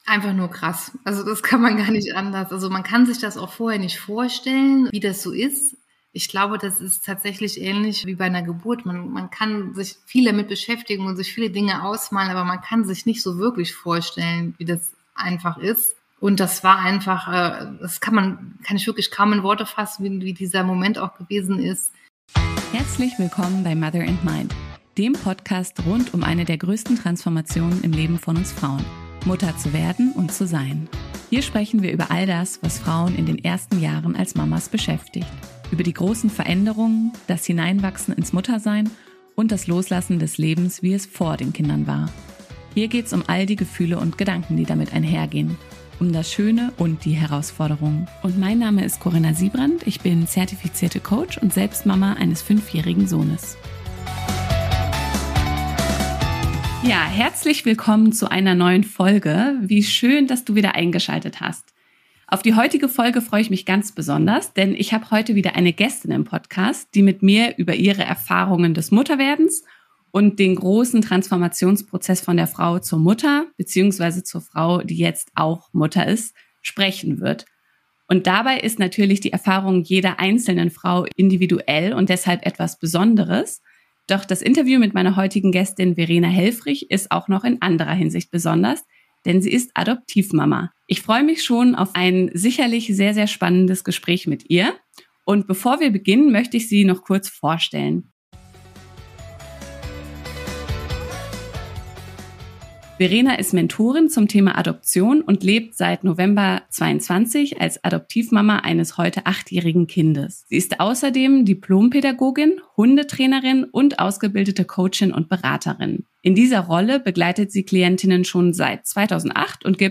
Hier im Podcast spreche ich mit Frauen darüber, wie sie persönlich das Mutterwerden und ihre Muttertät bzw. das Hineinwachsen ins Muttersein erlebt haben.